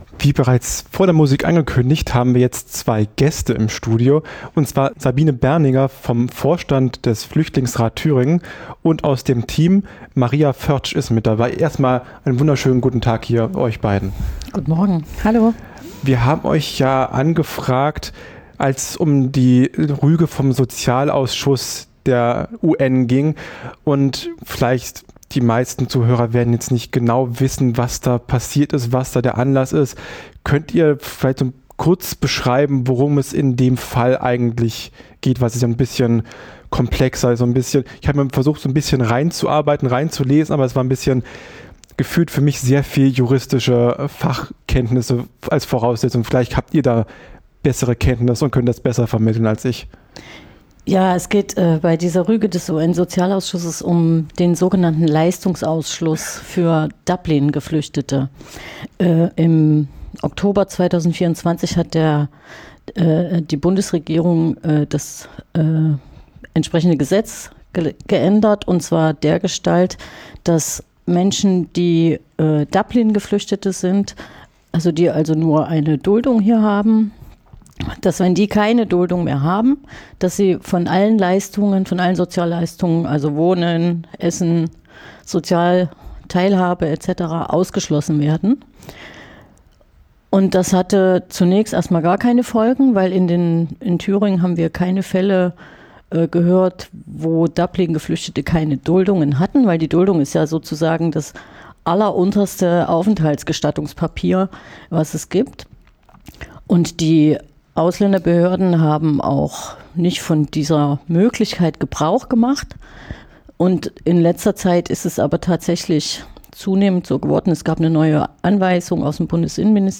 > Download Redaktioneller Hinweis: Das Interview wurde bereits Mitte November, also vor der Verleihung des Negativpreises, gef�hrt.